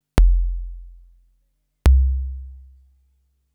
dcvirussub.wav